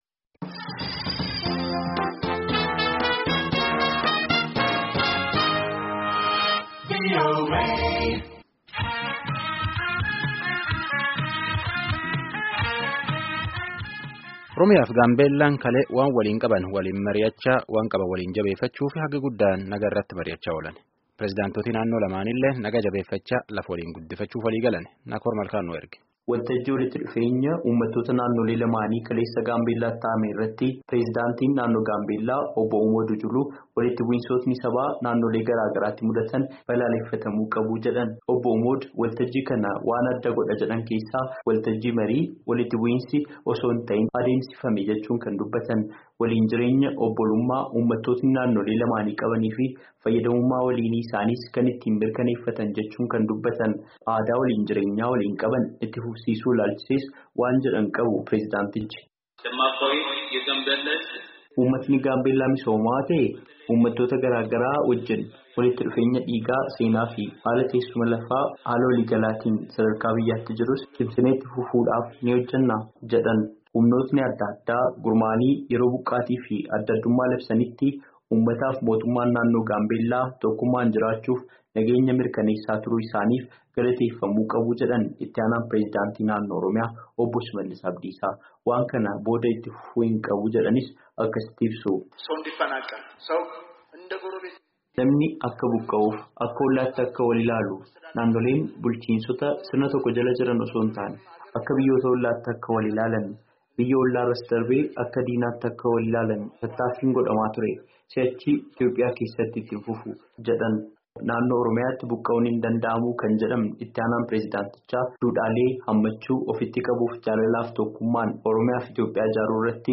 Namoonti waltajjichatti hirmaatan tokko tokko gaaffilee gaafataniif deebii argachuu isaanii dubbatan. Gabaasaa guutuu dhaggeeffadhaa.